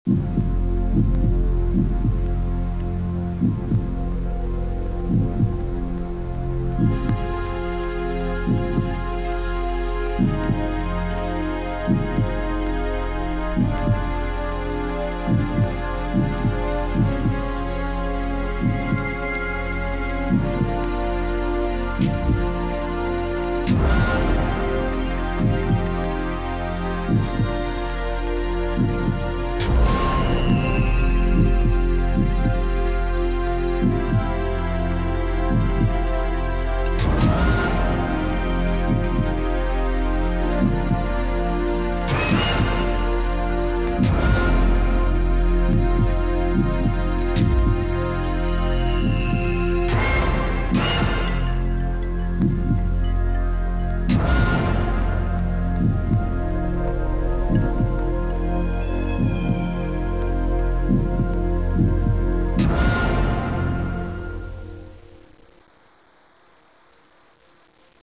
Música longa de suspense.